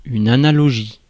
Prononciation
Synonymes métaphore Prononciation France: IPA: /a.na.lɔ.ʒi/ Le mot recherché trouvé avec ces langues de source: français Traduction 1.